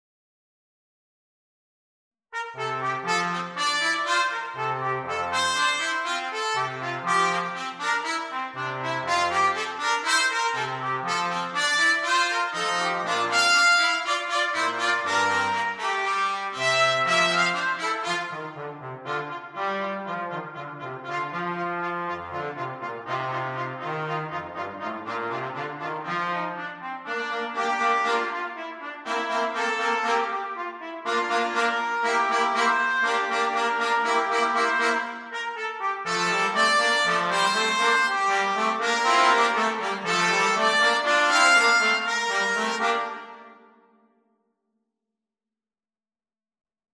Four fun movements, ending in a Calypso.